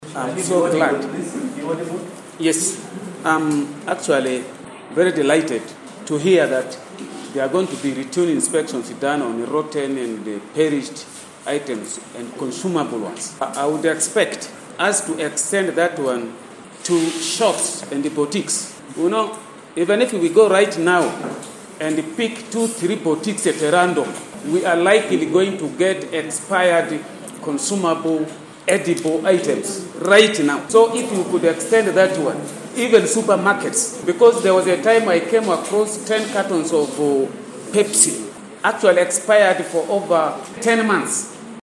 In a recent council sitting on Thursday, September 28th, 2023, the Arua Central Division Council in Arua City has taken a strong stance against traders who have been selling expired goods, particularly rotten meat, in the area.
Another council member, Hon Aligah Yunus Awaa, representing the male elders of Arua Central Division, echoed Alia's concerns, focusing on the appalling condition of meat being sold on the streets.